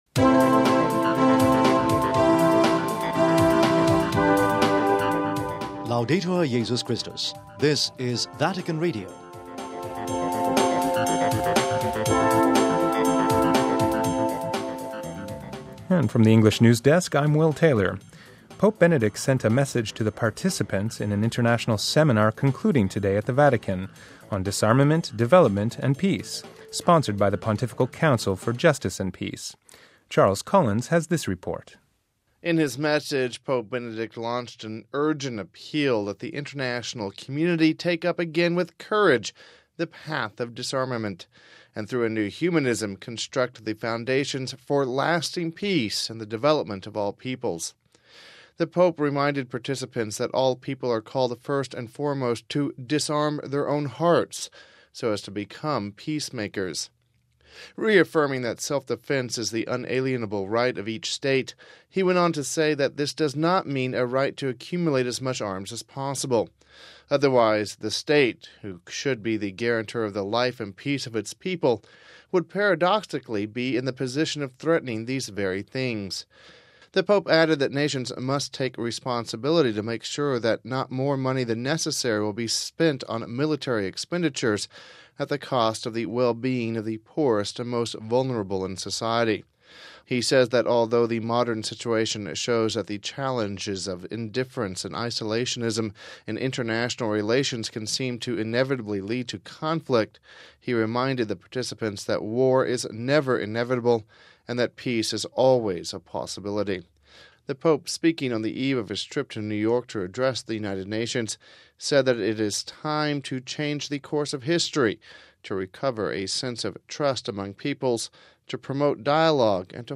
We have this report...